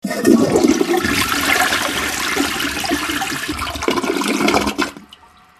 Звуки унитаза
Шум смыва воды в унитазе